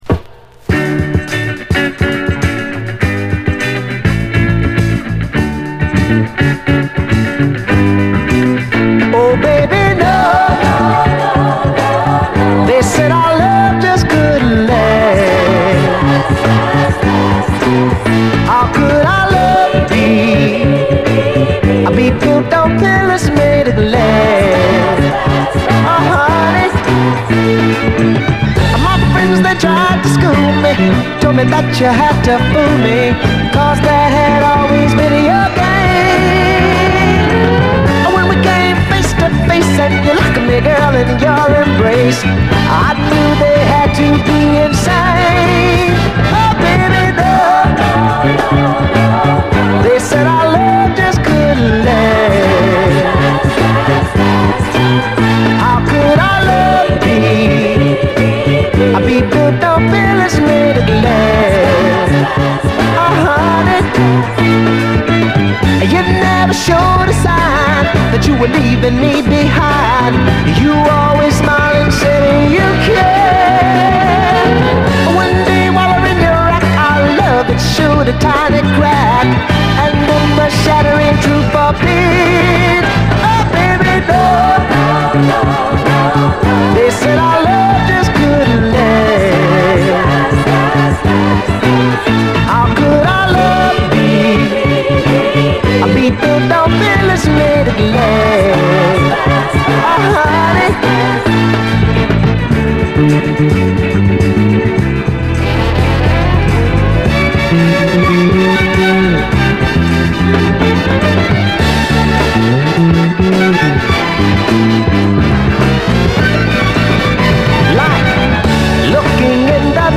SOUL, 70's～ SOUL, 7INCH
眩い輝きを放つ、黄金のキラー・ヤング・ソウル！